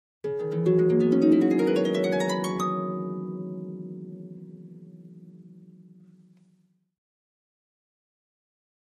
Harp, Ascending Gliss, Type 2